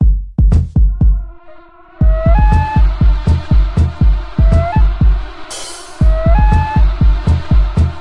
嘻哈节拍120bpm " 节拍120bpm03
标签： 120BPM 量化 节奏 臀部 有节奏
声道立体声